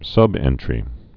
(sŭbĕntrē)